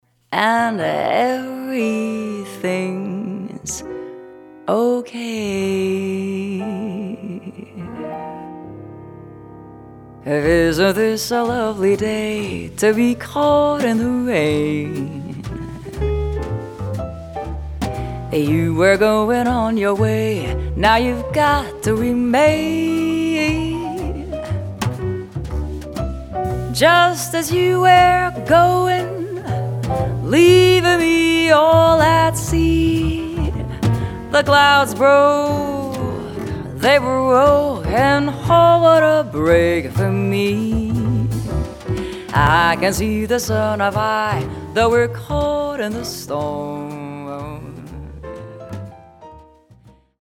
Vocalist
Grand Piano
Double-Bass
Drums
Guitar
Violin
Trombone